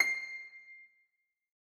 Harpsicord
c7.mp3